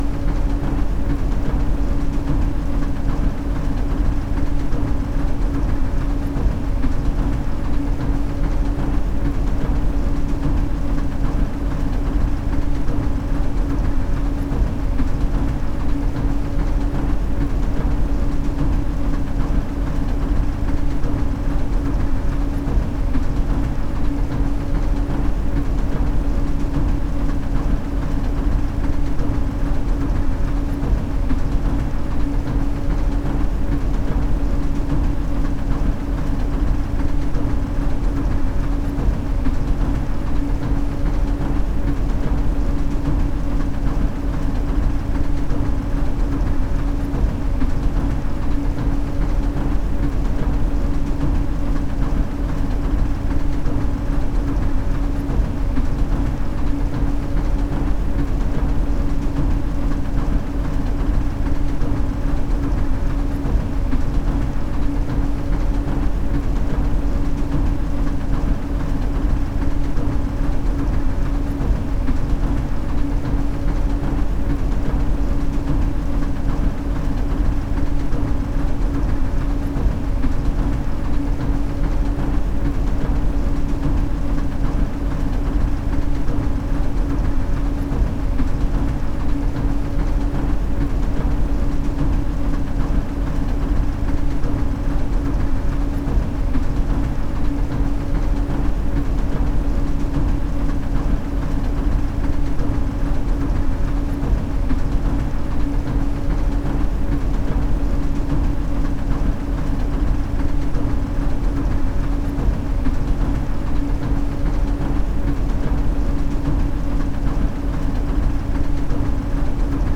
街の環境音１（日常系）
エスカレーター１
escalator1.mp3